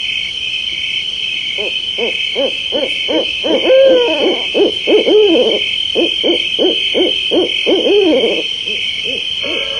AMBIANCES SONORES DE LA NATURE
Nuit d'été 01(grenouilles)